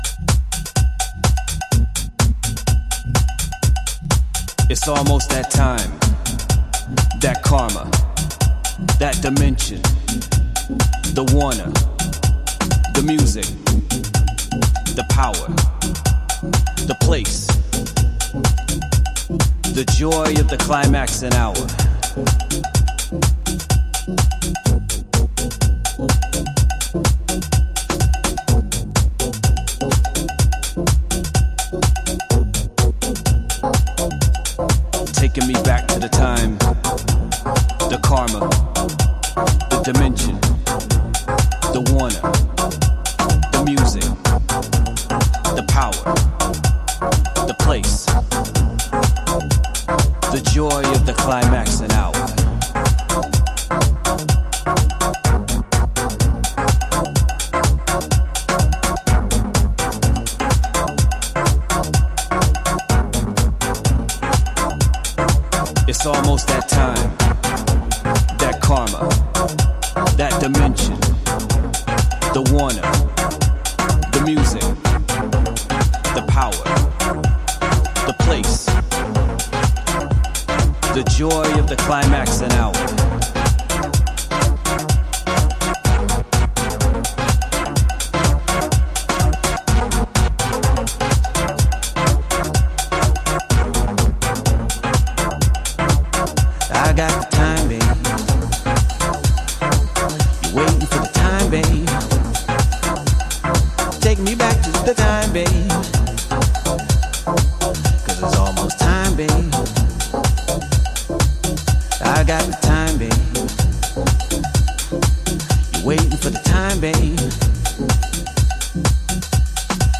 ブレイク明け昇天必至なトランシーさマシマシの3トラック。